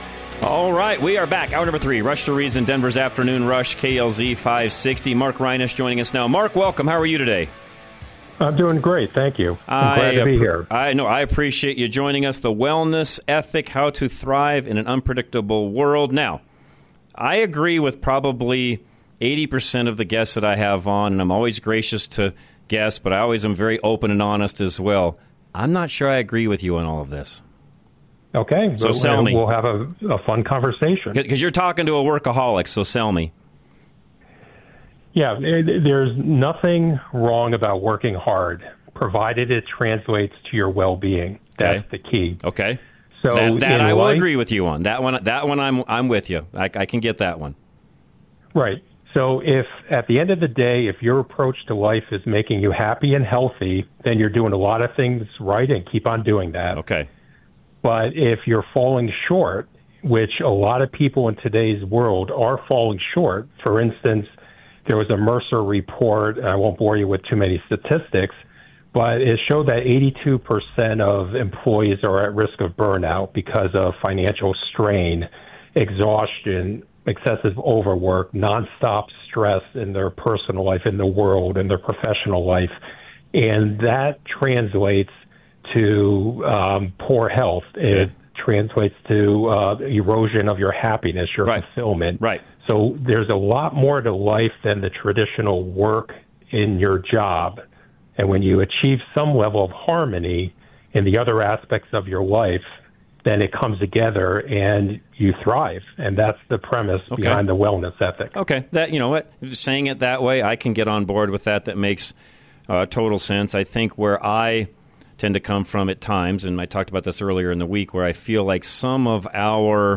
Interviews What If Everything You've Been Told About "Work Ethic" Is Wrong?